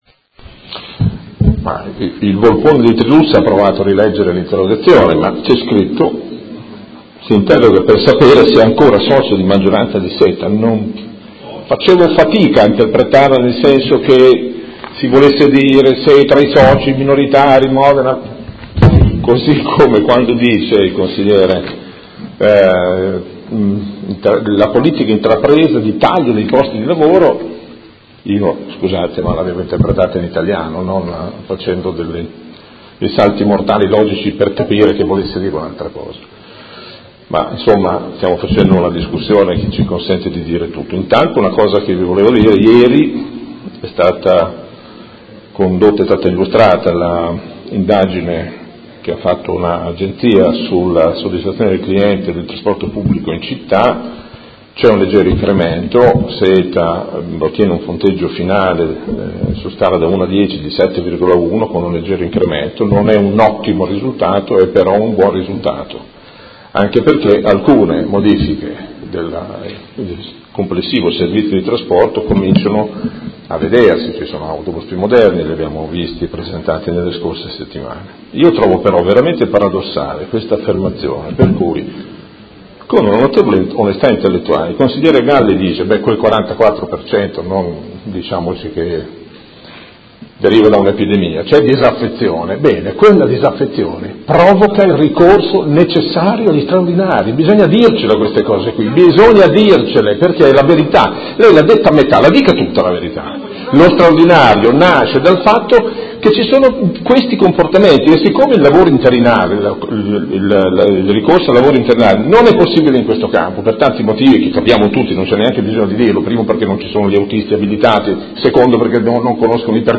Gabriele Giacobazzi — Sito Audio Consiglio Comunale
Seduta del 13/07/2017 Conclusioni.